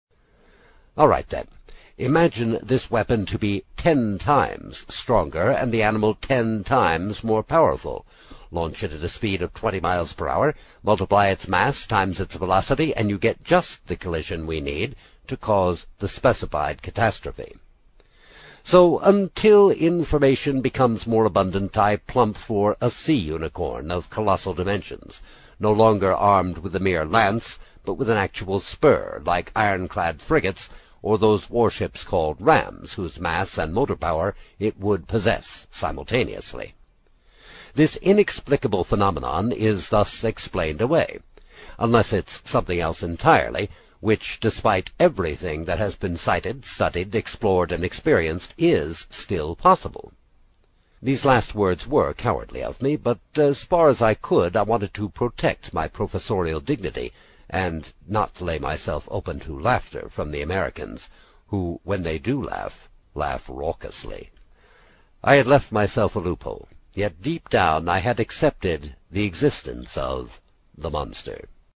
英语听书《海底两万里》第18期 第2章 正与反(7) 听力文件下载—在线英语听力室
在线英语听力室英语听书《海底两万里》第18期 第2章 正与反(7)的听力文件下载,《海底两万里》中英双语有声读物附MP3下载